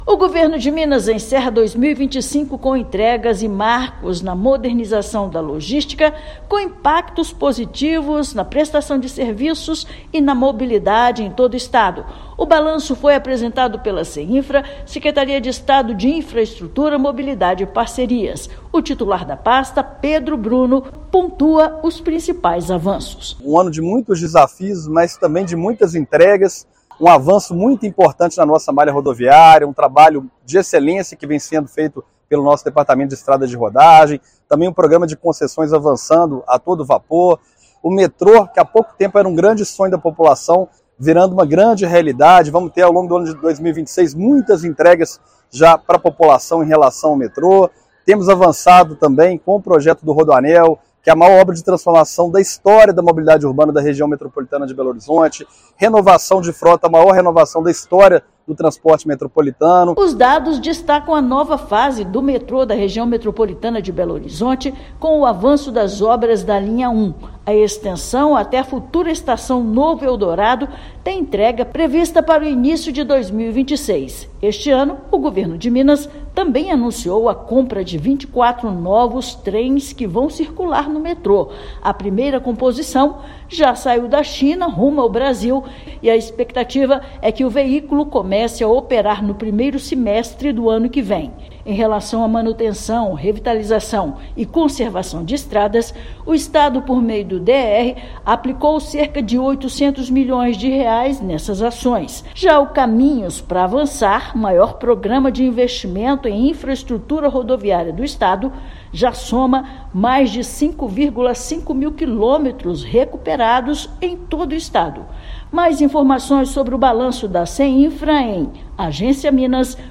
Estado registra a entrega de 850 novos ônibus metropolitanos, investimentos de R$ 800 milhões na malha rodoviária e sucesso nos leilões de concessões e PPPs. Ouça matéria de rádio.